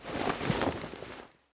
looting_5.ogg